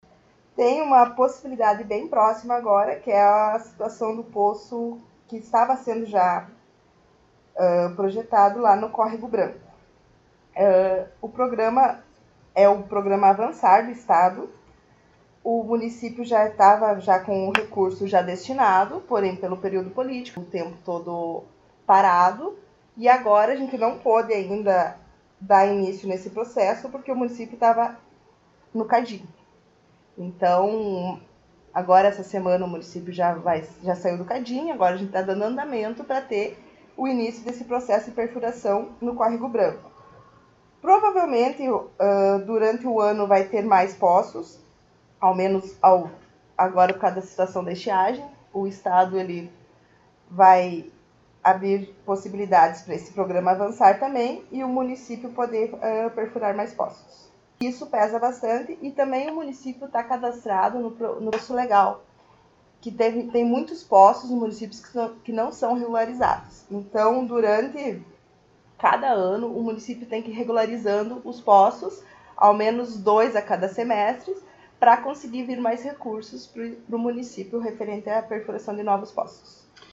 Secretária Municipal do Meio Ambiente concedeu entrevista